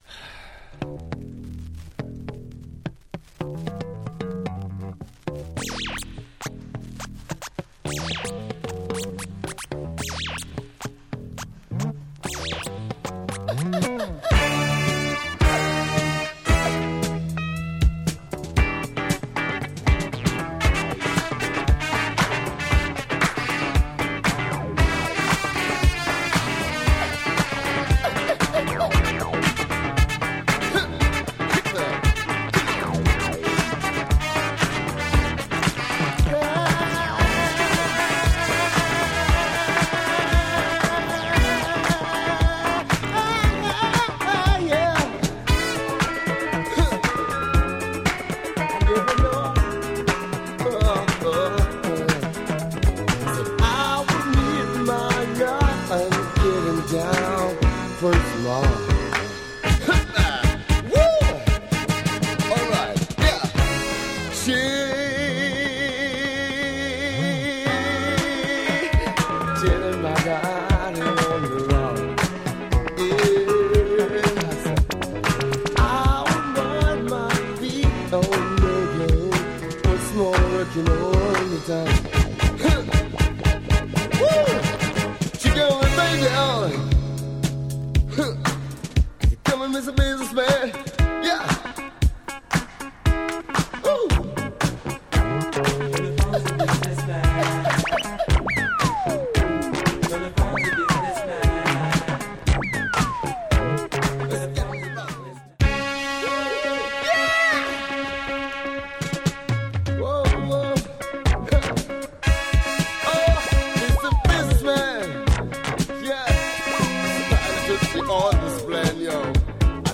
Beautiful copy for this West Indian boogie funk holy grail.